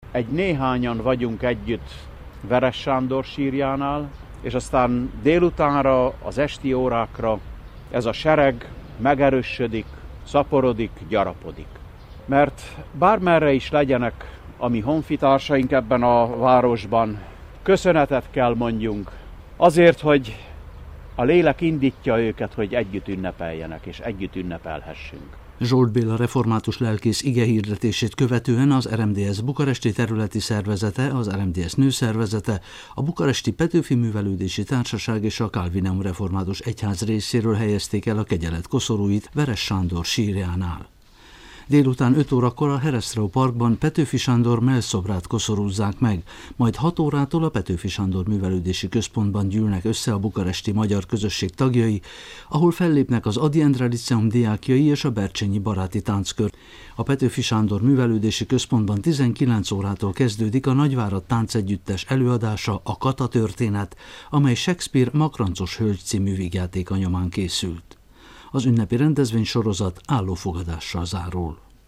Bukarestben Veress Sándor honvéd százados, a református egyház egykori főgondnokának sírjánál tartott megemlékezéssel és koszorúzással kezdődtek a nemzeti ünnepnek szentelt rendezvények.